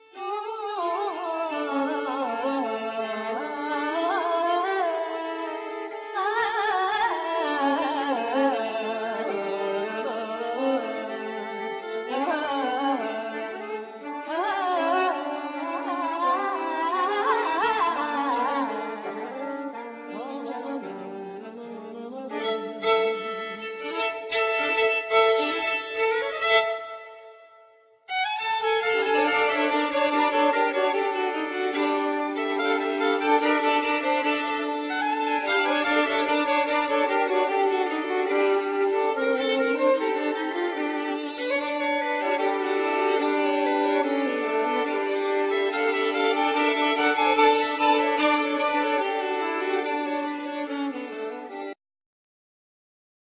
Violin, Voice